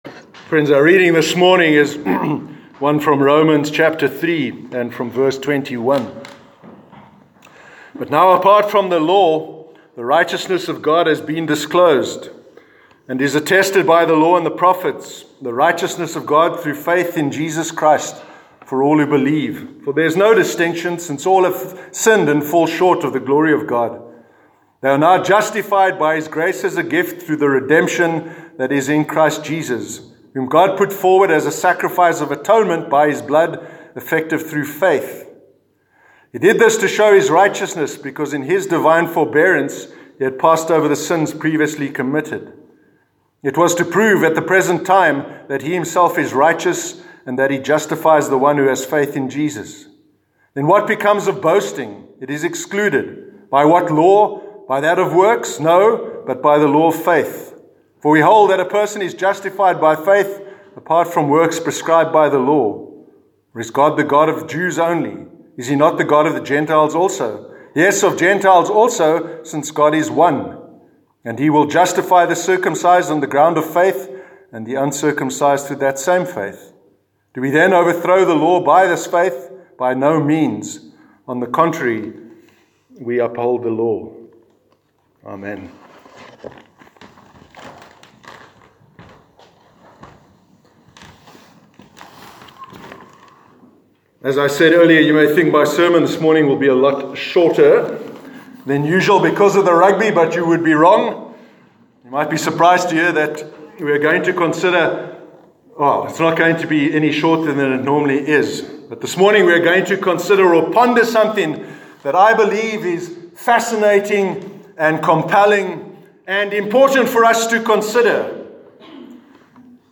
Faith Alone- Sermon 20th October 2019